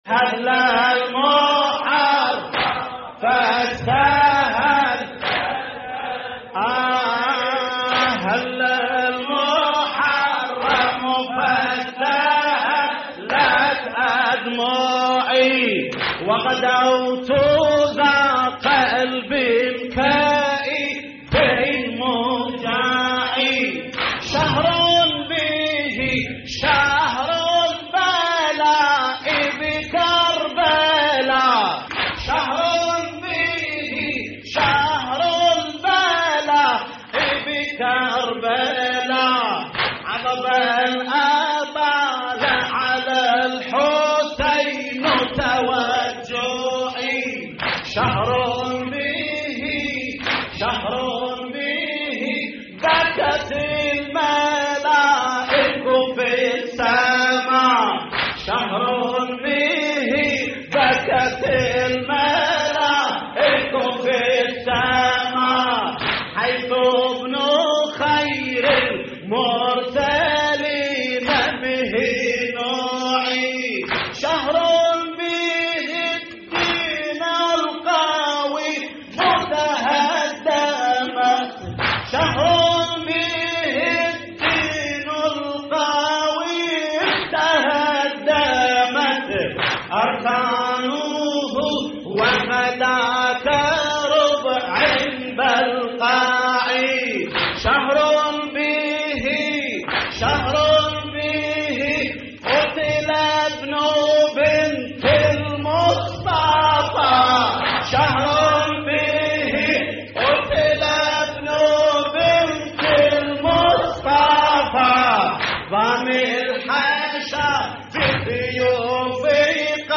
تحميل : هلّ المحرم فاستهلت أدمعي وغدوتُ ذا قلب كئيب موجع / الرادود جليل الكربلائي / اللطميات الحسينية / موقع يا حسين